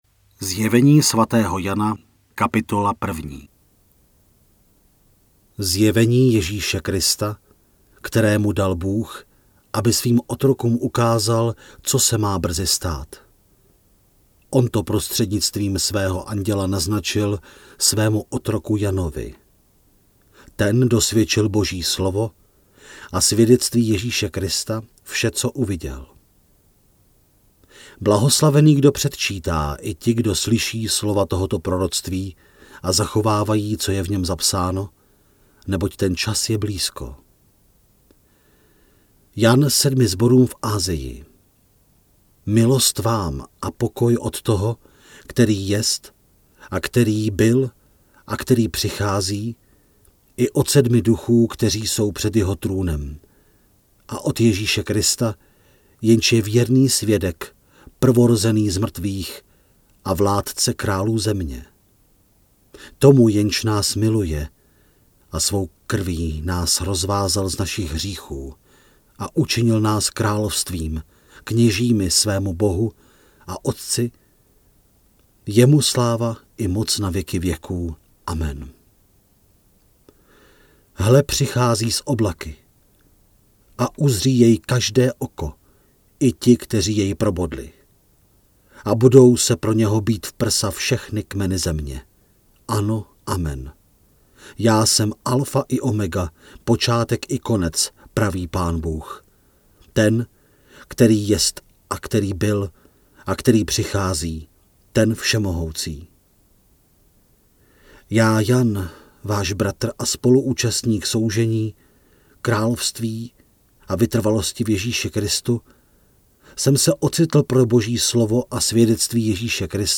Načtená kniha je rozdělená na 22 částí, které odpovídají dělení kapitol.
Stahujte celé zde (75MB):  ZJEVENÍ SVATÉHO JANA – audiokniha, Studio Vox 2018